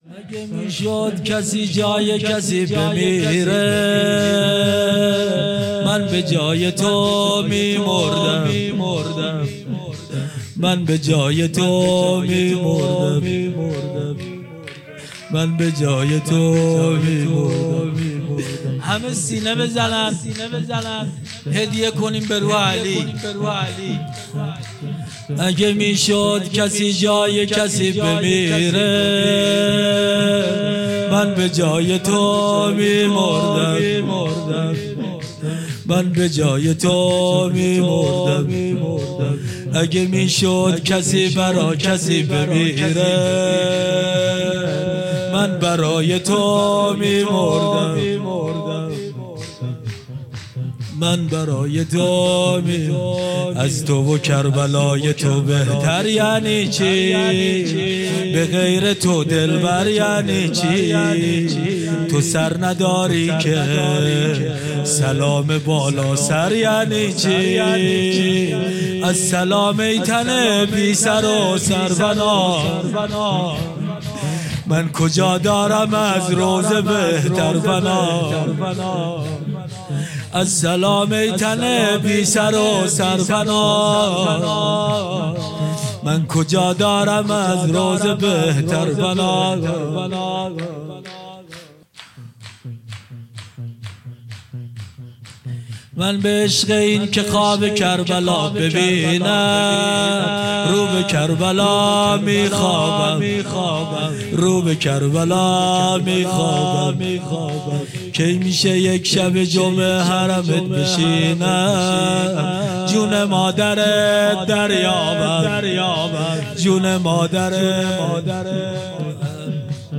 مجلس روضه هفتگی ۸ خرداد سال ۱۴۰۴